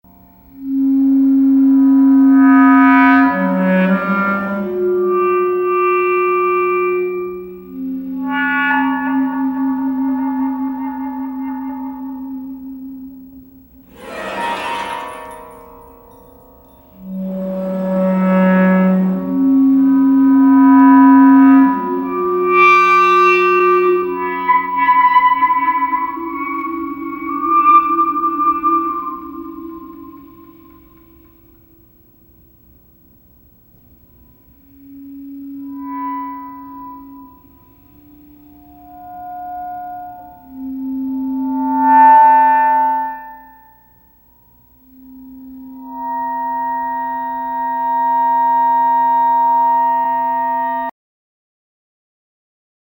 Clarinet and Electronics